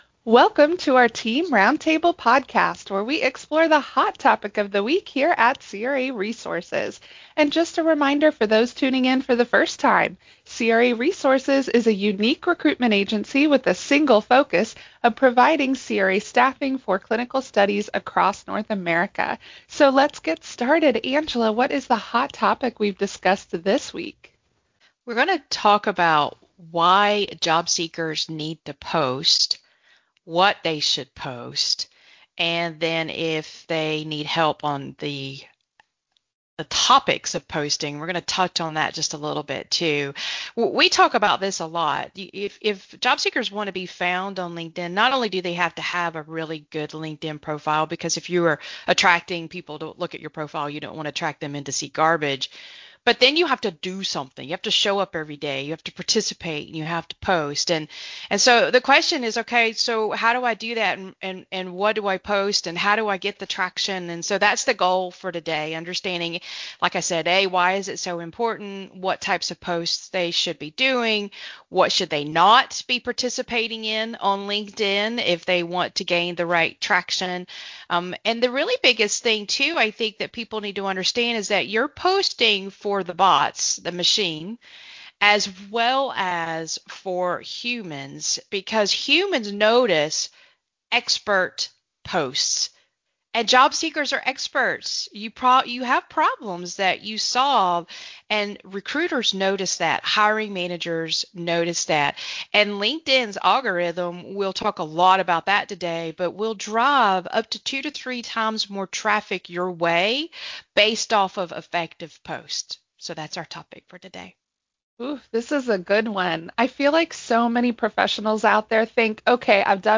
Roundtable: LinkedIn Posts for Job Seekers - craresources